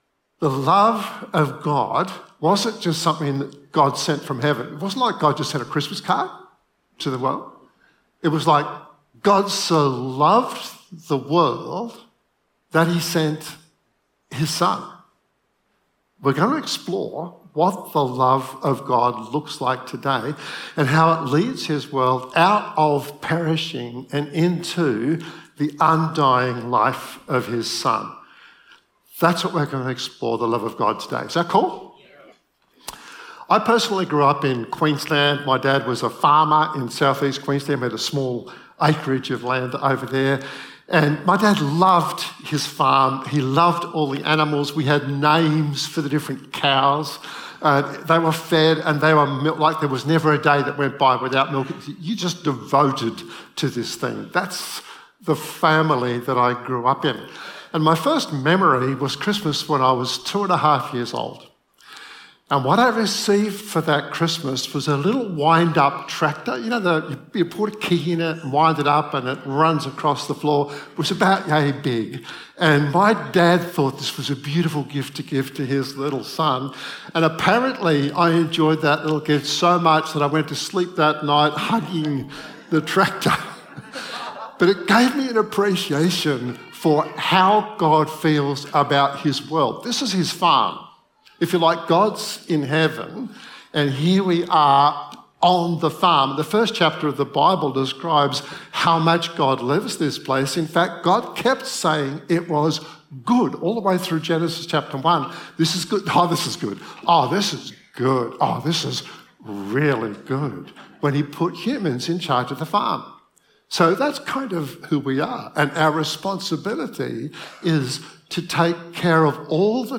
The Arrival of Love was recorded at Riverview 2022-12-11.